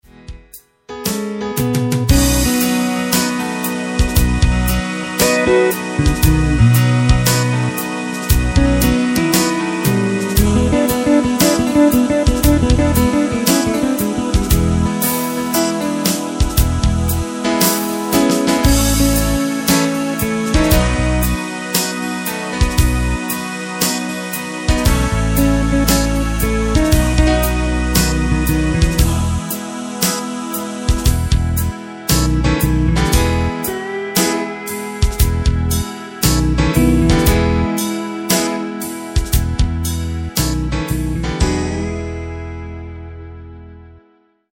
Takt:          4/4
Tempo:         116.00
Tonart:            E
Rock - Pop aus dem Jahr 1974!